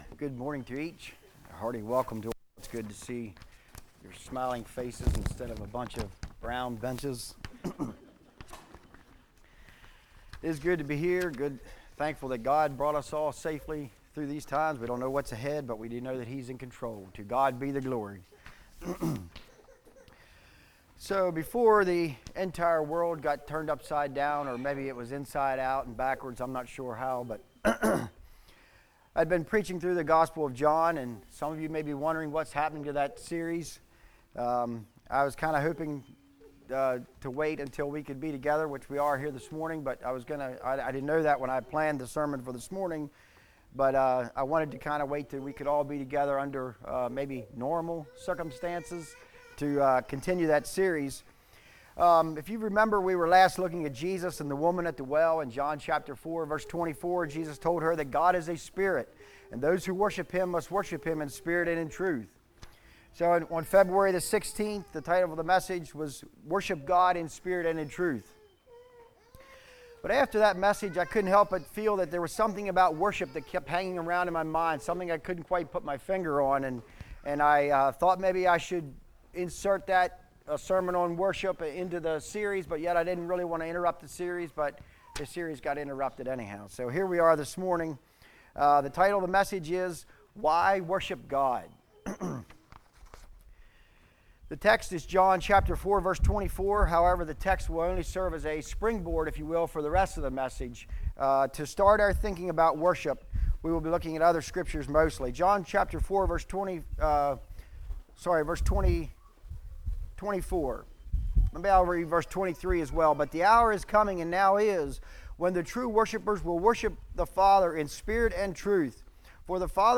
23-24 Service Type: Message Bible Text